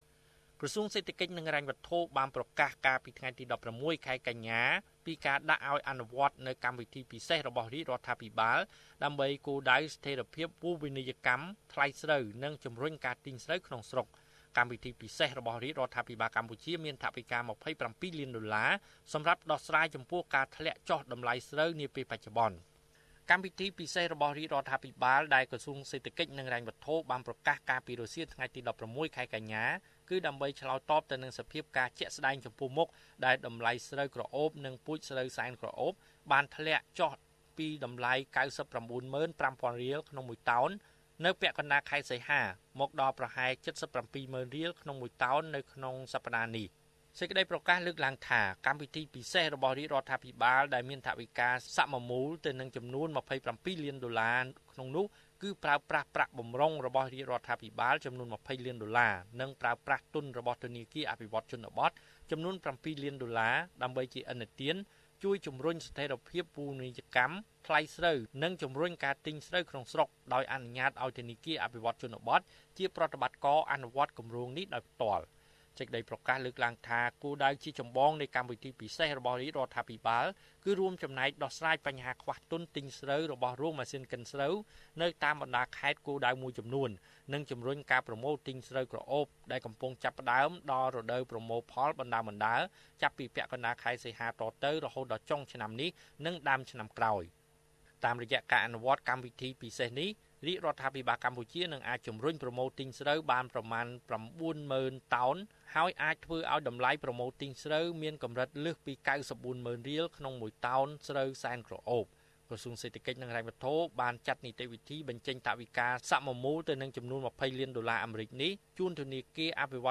សូមចុចសំឡេងដើម្បីស្តាប់របាយការណ៍លំអិត។